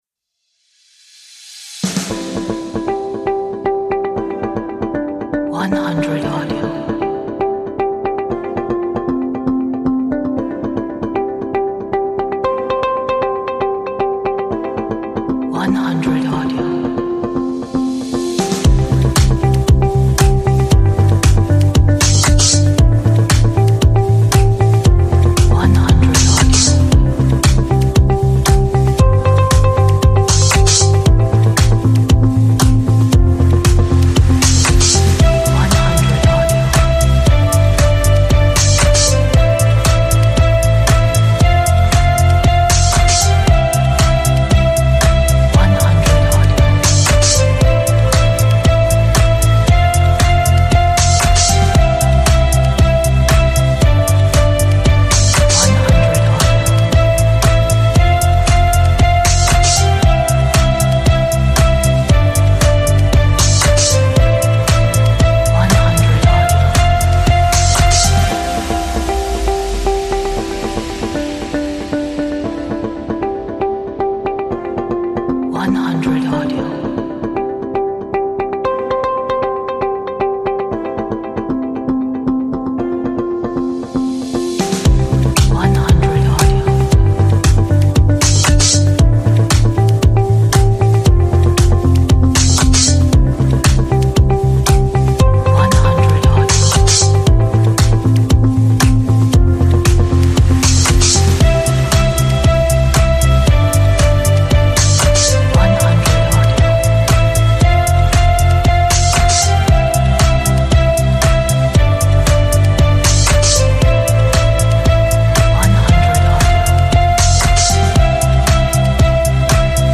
a nice pop upbeat inspirational 这是一首很好的鼓舞人心流行乐